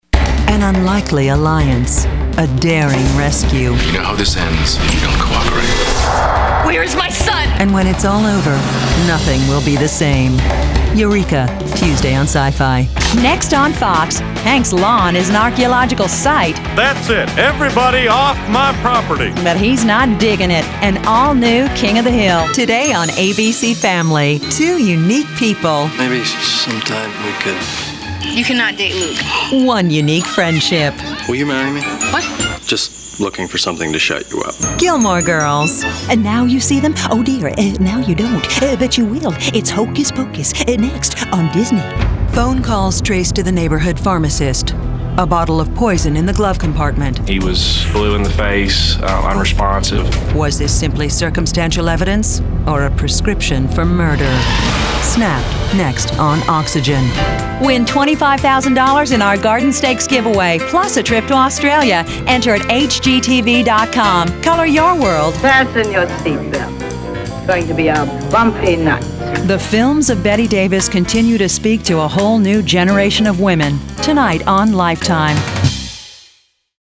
Female Voice Over Talent